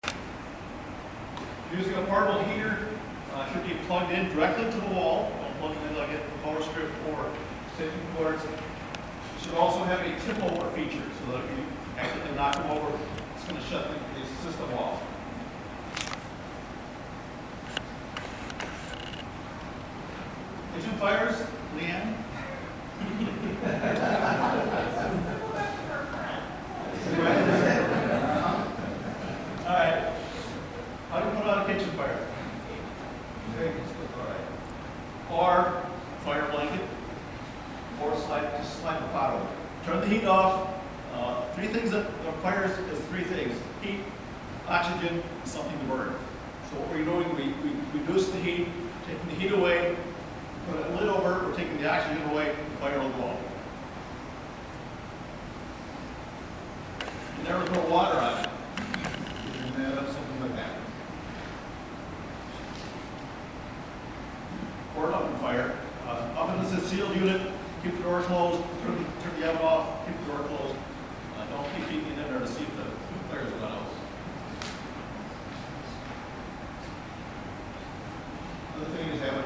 Fire inspector gives fire safety tips during Fort McCoy's Safety, Occupational Health Council meeting, Part 3